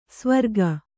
♪ svarga